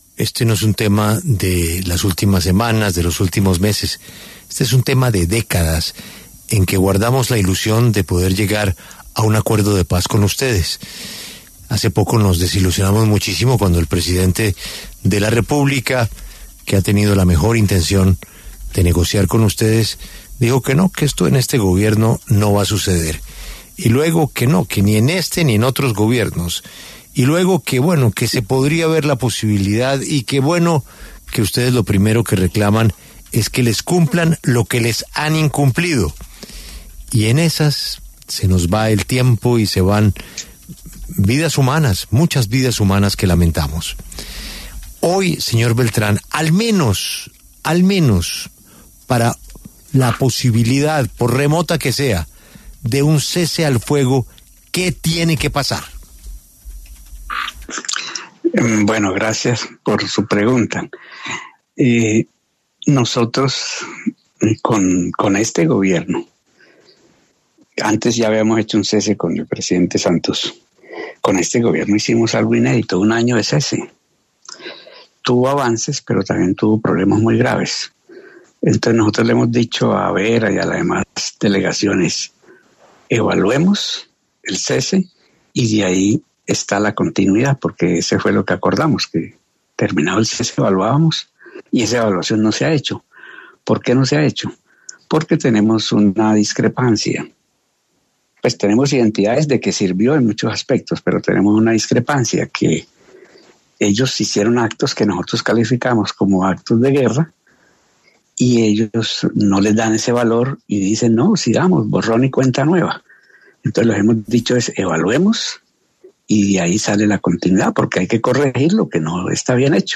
En diálogo con Julio Sánchez Cristo para La W, Pablo Beltrán, jefe negociador del Ejército de Liberación Nacional (ELN) con el Gobierno Nacional, se pronunció acerca del reinicio de los diálogos de paz.